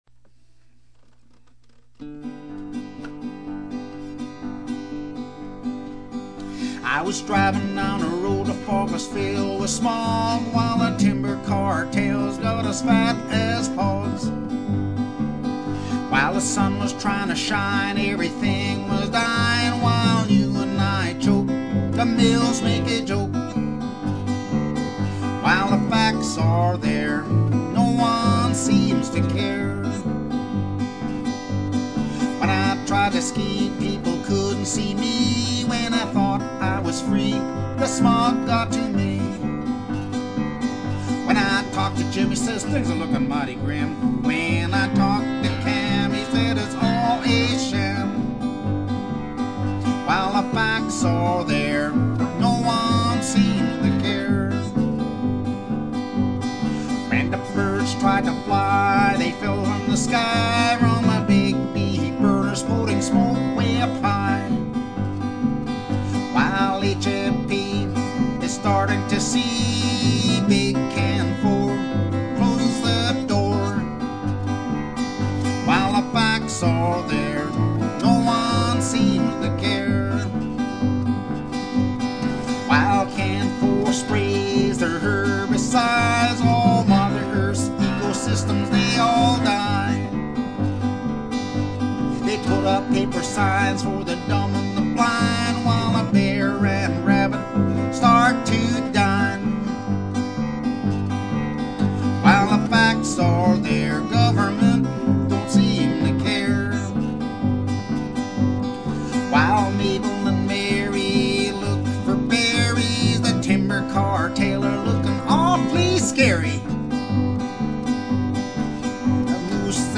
Guitar, Vocals
Bass